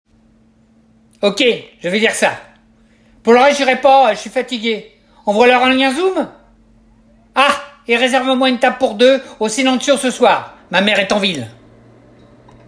Voix marionnette
36 - 60 ans - Ténor